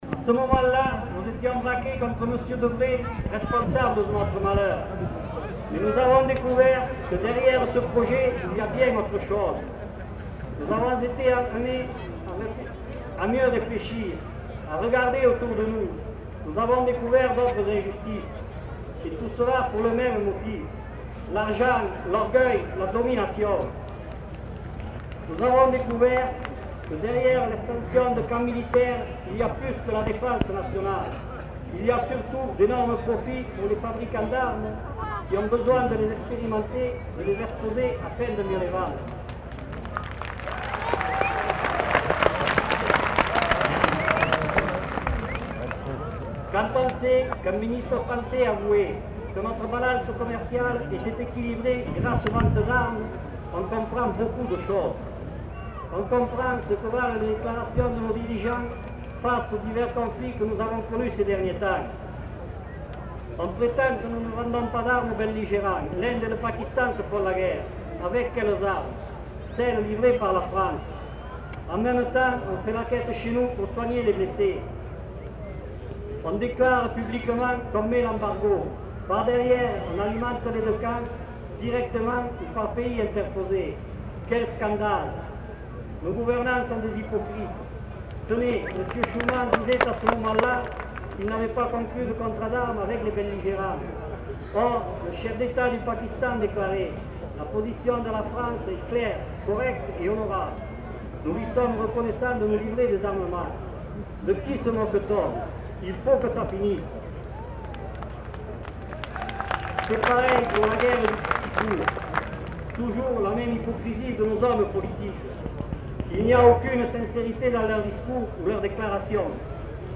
Lieu : [sans lieu] ; Aveyron
Genre : parole
Notes consultables : Les allocuteurs ne sont pas identifiés.